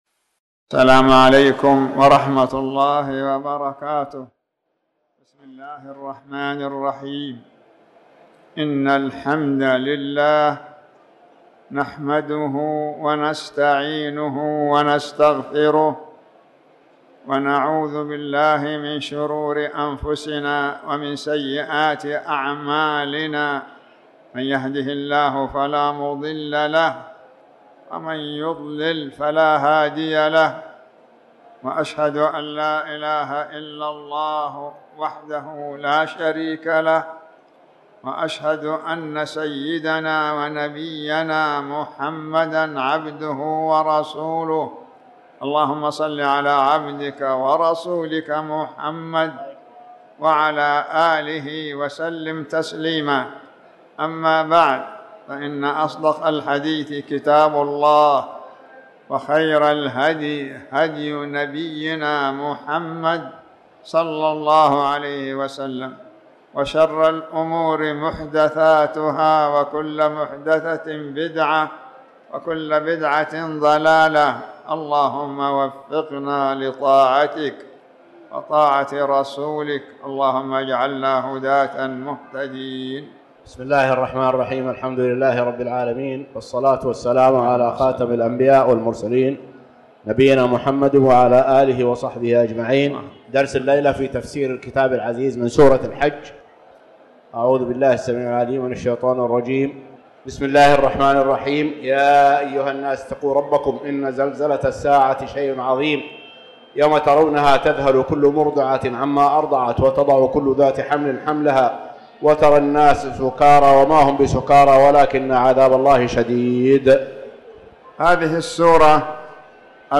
تاريخ النشر ٢٨ ربيع الثاني ١٤٣٩ هـ المكان: المسجد الحرام الشيخ